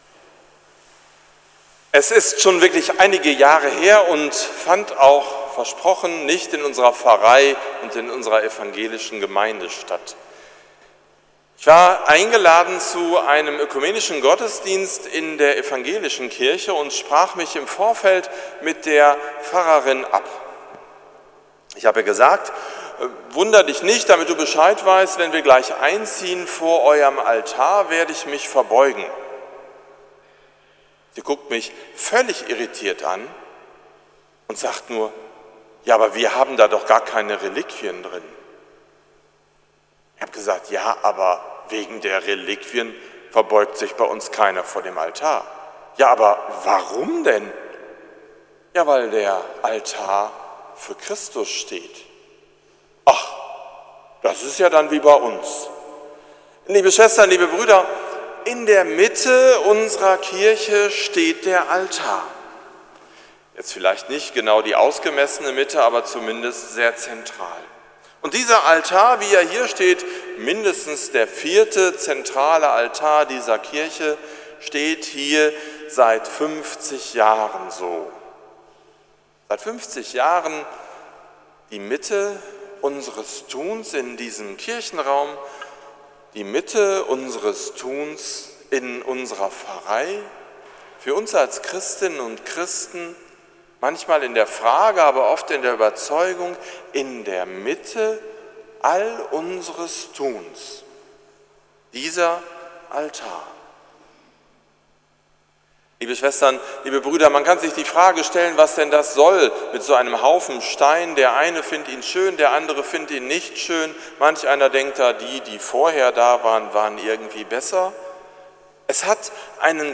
Predigt zum 27. Sonntag im Jahreskreis – 50 Jahre Altarweihe in der St. Nikolaus-Kirche Wolbeck – St. Nikolaus Münster
zum 50. Weihejubiläum des Altars in der St. Nikolaus-Kirche in Wolbeck
predigt-zum-27-sonntag-im-jahreskreis-50-jahre-altarweihe-in-der-st-nikolaus-kirche-wolbeck